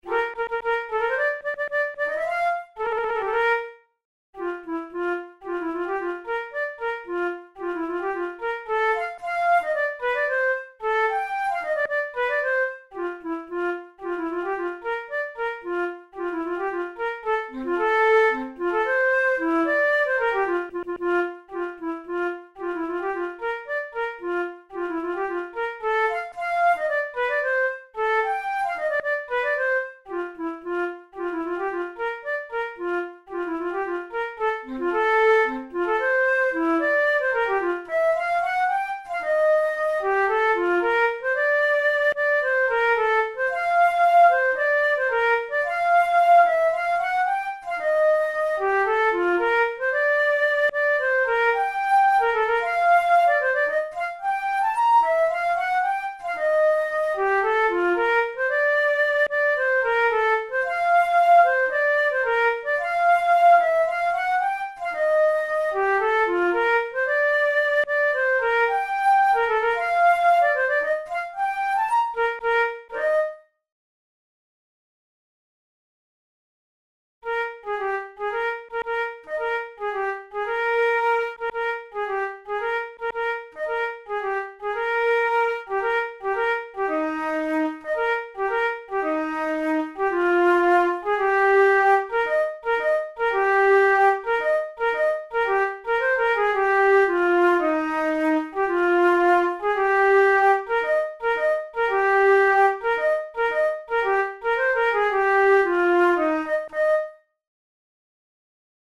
Austrian military march
Categories: Marches Military music Piccolo tunes Difficulty: easy